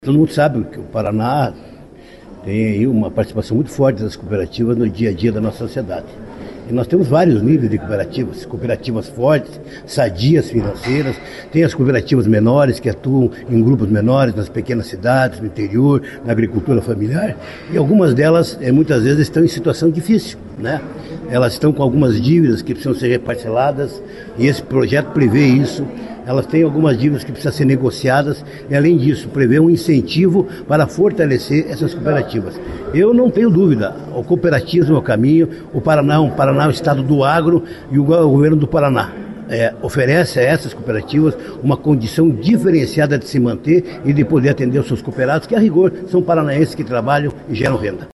O líder do governo, Hussein Bakri (PSD), defendeu a aprovação da proposta.